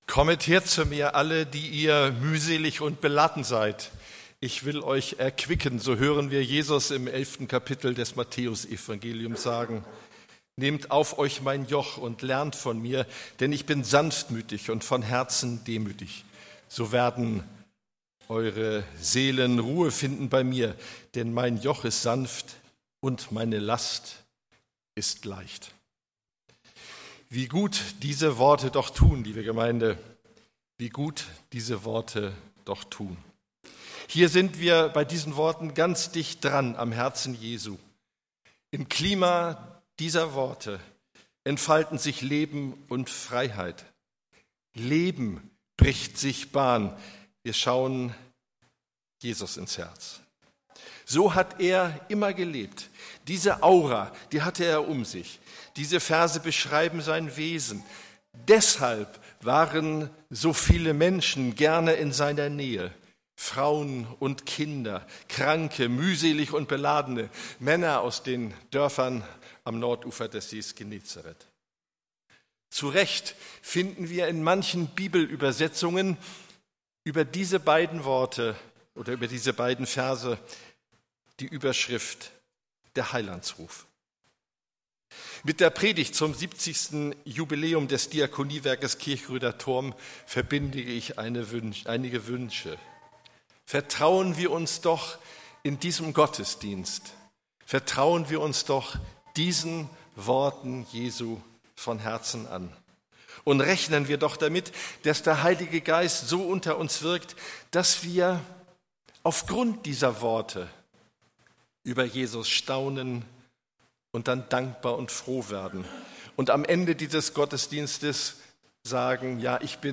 Predigttext: Matthäus 11, 28-30
Gottesdienst